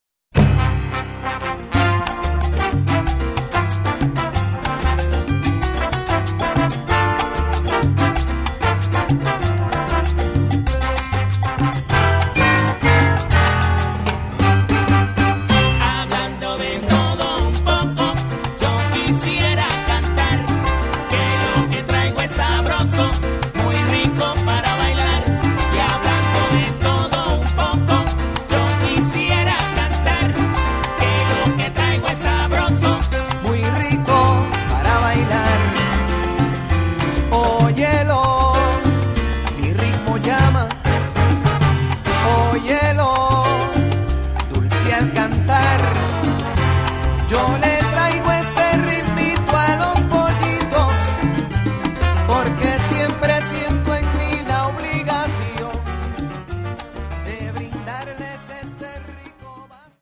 Category: salsa
Style: mambo
Solos: flute, vocal
Instrumentation: salsa (little big band) 2-1-3, rhythm (4)
Featured Instrument: vocalist